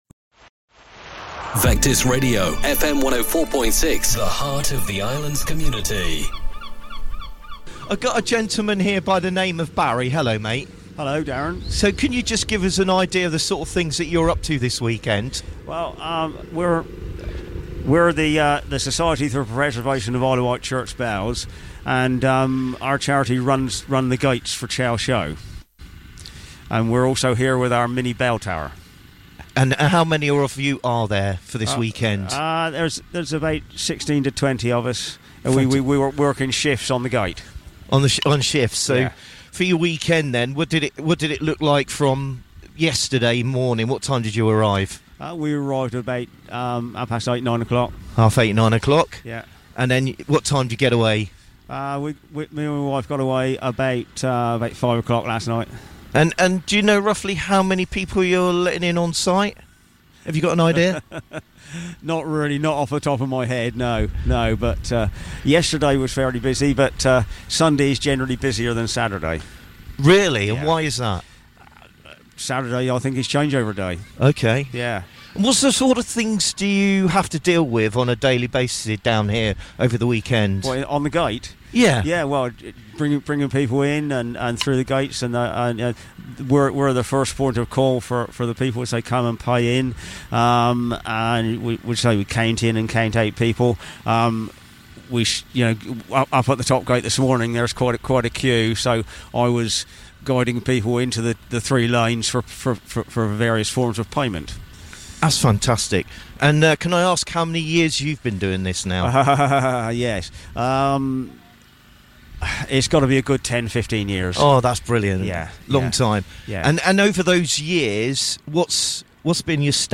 a Trustee of The IW Church Bell Preservation Society at The Chale Show 2025.